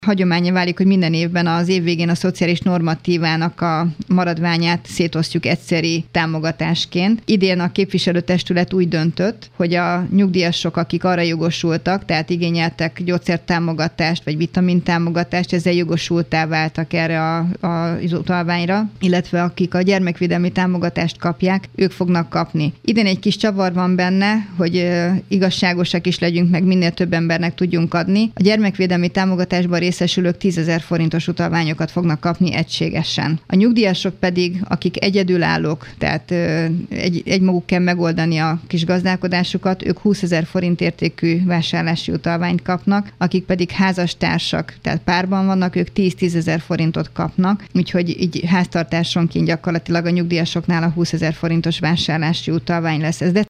Hajnal Csilla polgármestert hallják.